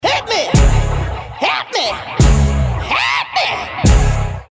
• Качество: 320, Stereo
jazz